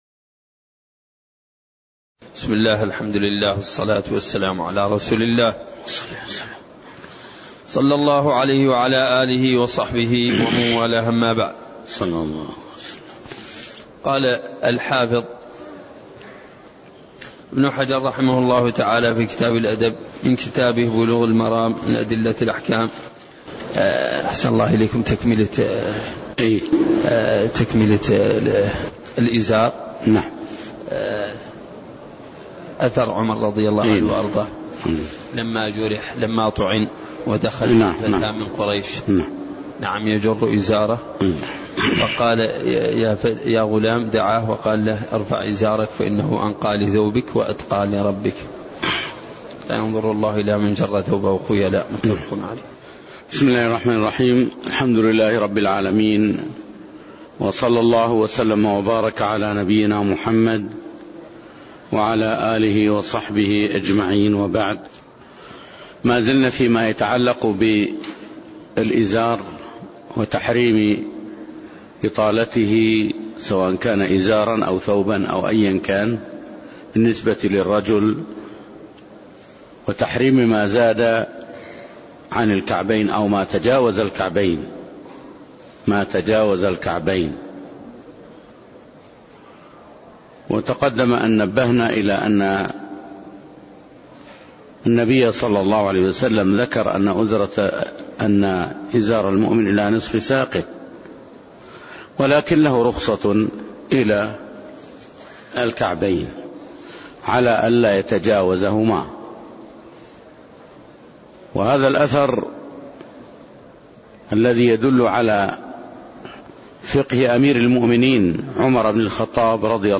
تاريخ النشر ١١ رجب ١٤٣١ هـ المكان: المسجد النبوي الشيخ